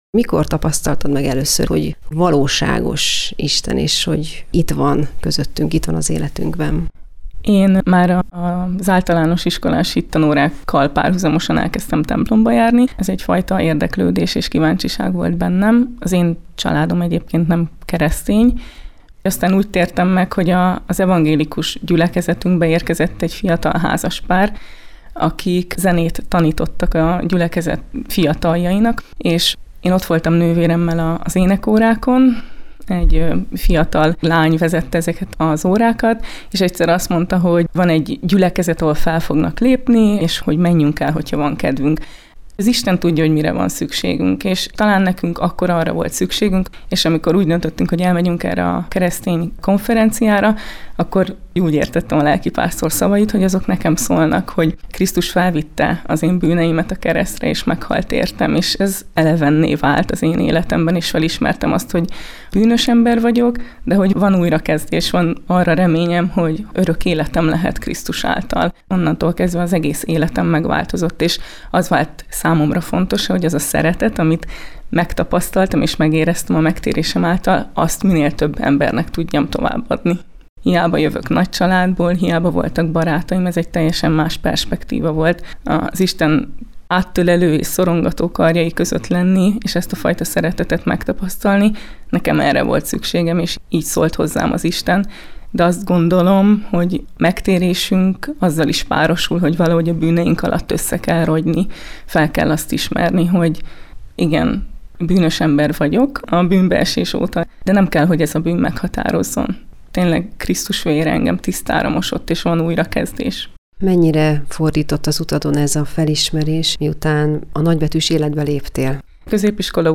„Krisztus szeretete nélkül mindannyian lelki hajléktalanok vagyunk" – fogalmaz a Magyar Evangélikus Rádiómisszió interjújában, amelynek részletét osztjuk meg a Missziói percekben.